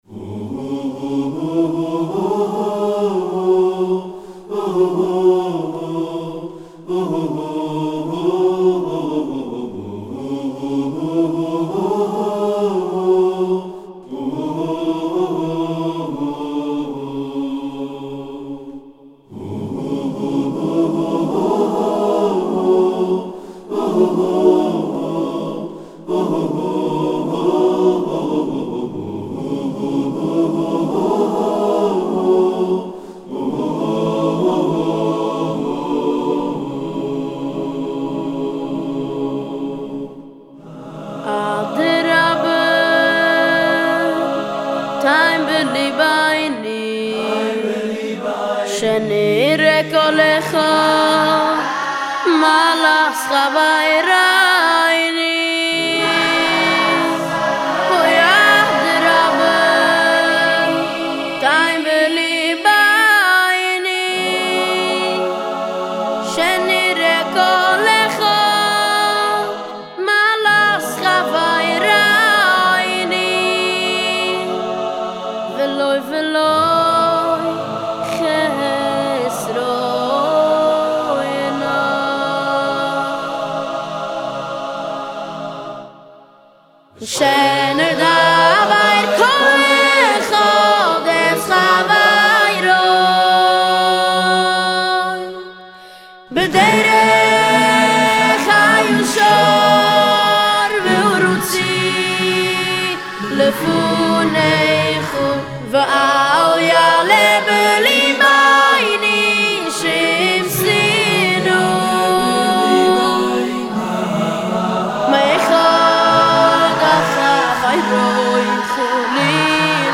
גרסה ווקאלית
מקהלת הילדים החסידית